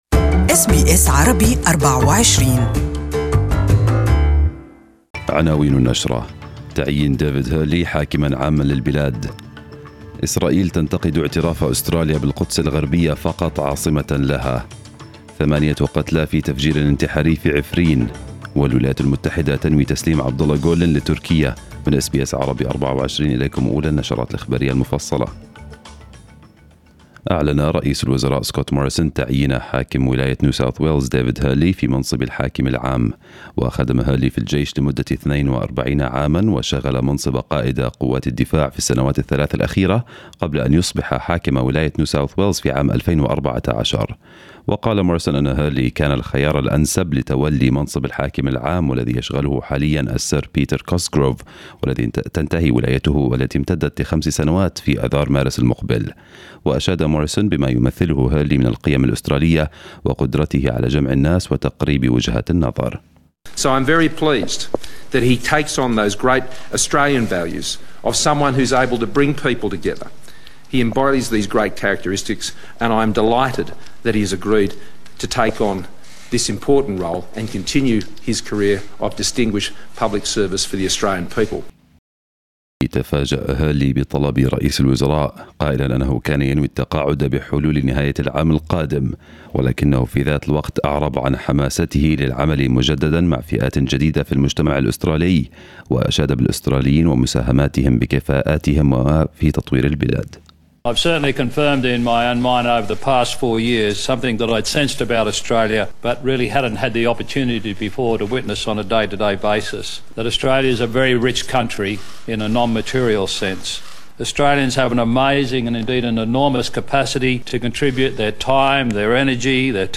نشرة الأخبار المفصلة لهذا الصباح